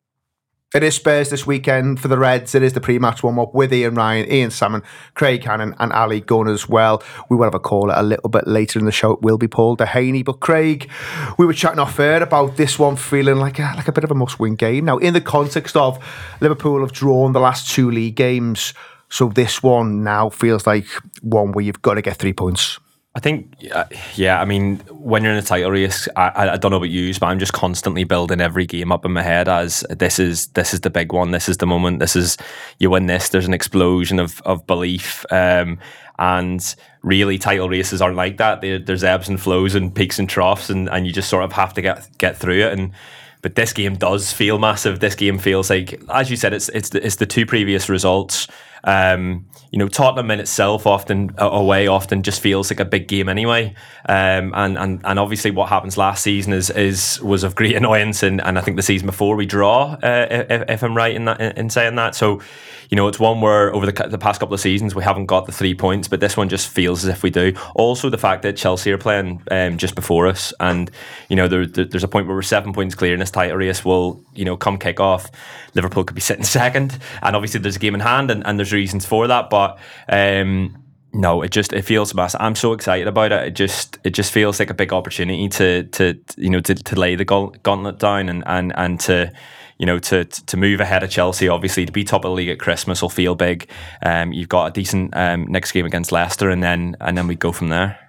Below is a clip from the show – subscribe for more pre-match build up around Everton v Liverpool…